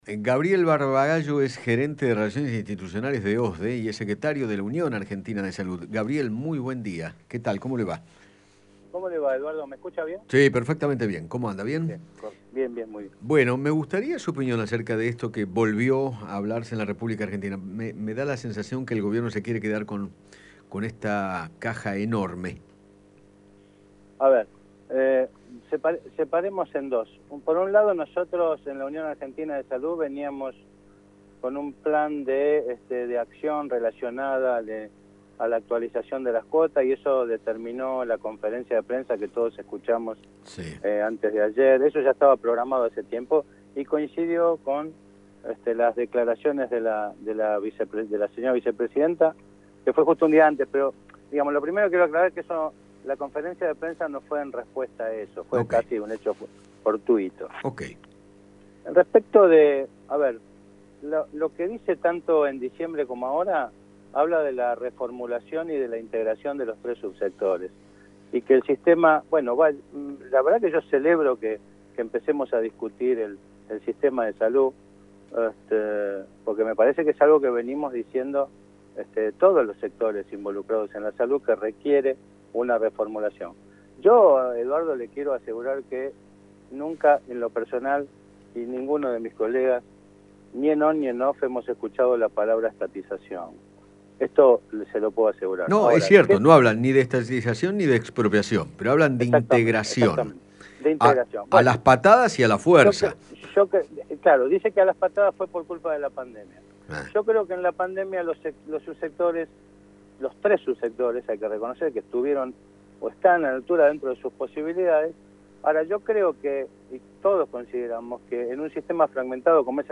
conversó con Eduardo Feinmann sobre la posible estatización de las empresas de medicina prepaga y se refirió al deterioro del sistema de salud desde el punto de vista económico.